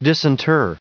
121_disinter.ogg